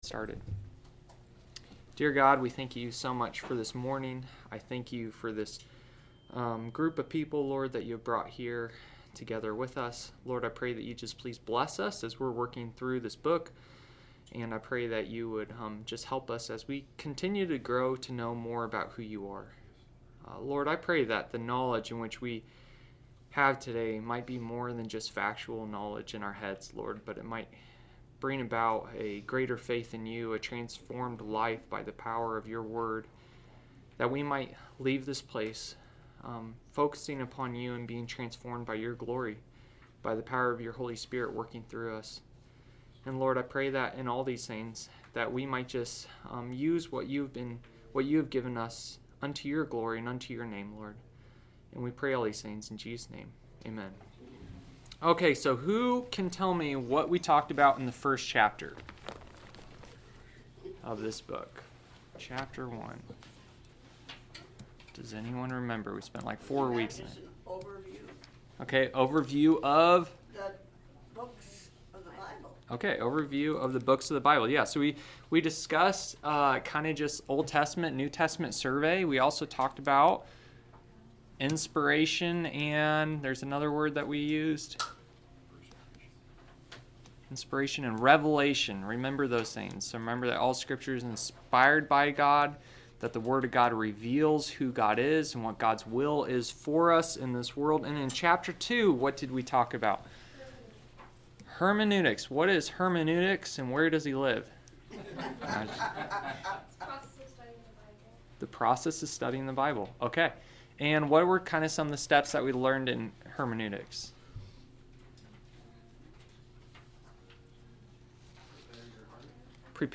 Sermons | New Hope Baptist Church
Adult Sunday School 11/30/25